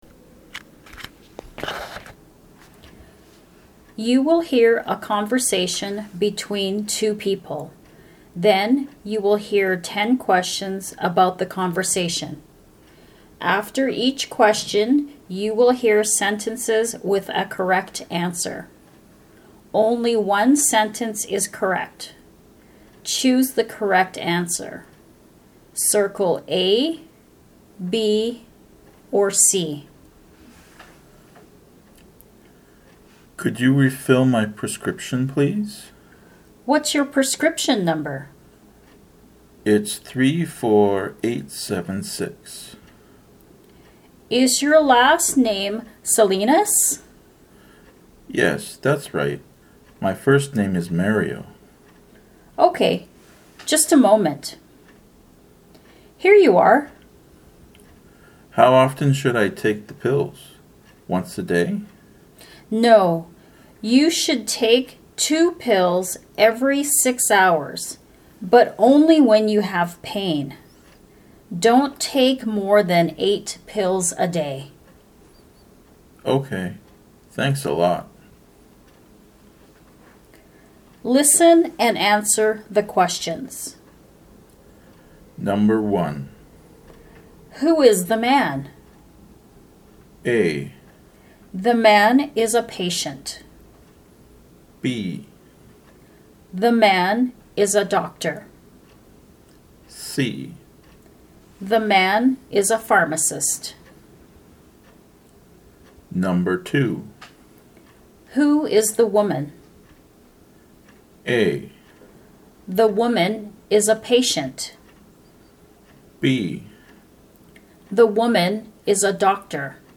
LINC 3 Listening to a Conversation at the Pharmacy
Conversation-Patient-and-Pharmacist.mp3